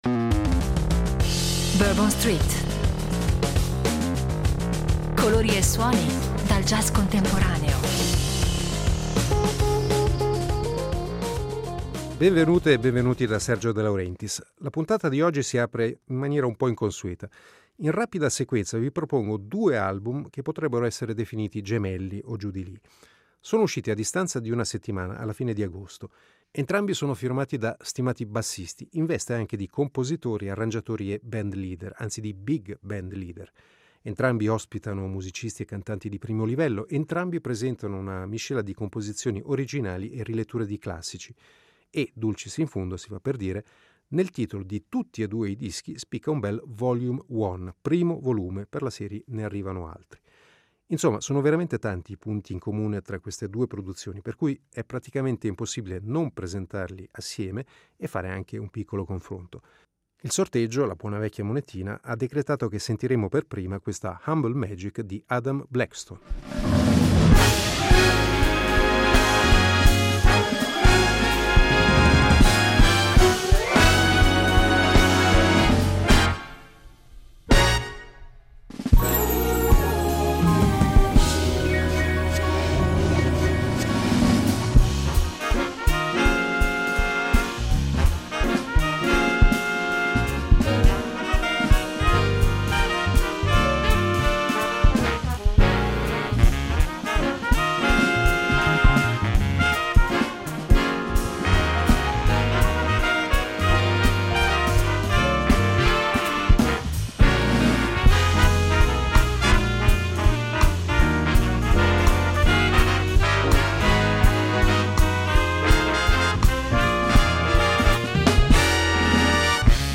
Le nuove uscite di fine agosto 2025, ospiti di prestigio e voci femminili di talento